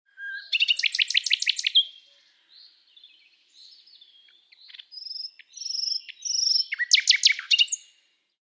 Ringtone: Nightingale
Download the song of a nightingale to use as your ringtone.